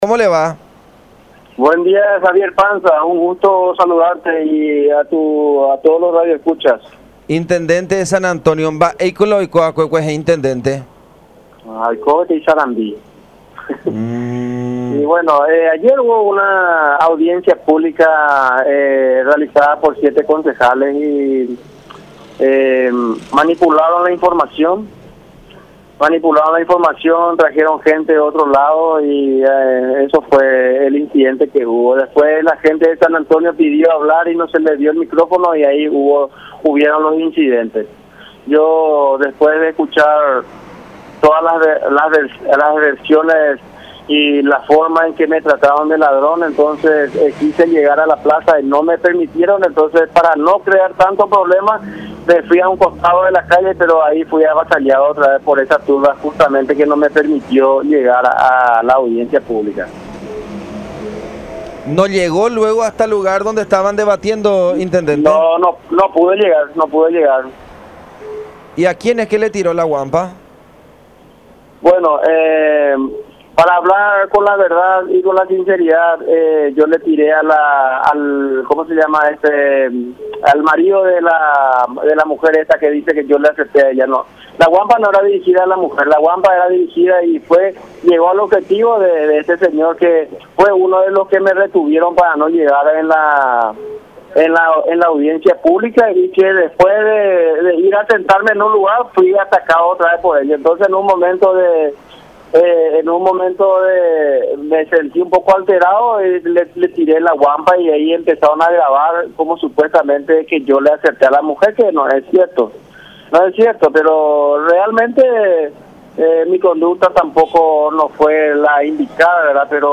“Si agredí a alguien, pido disculpas. Mi conducta no fue la indicada, pero son cosas que uno no maneja cuando está un poco nervioso”, expresó en diálogo con La Unión.
11-RAUL-MENDOZA.mp3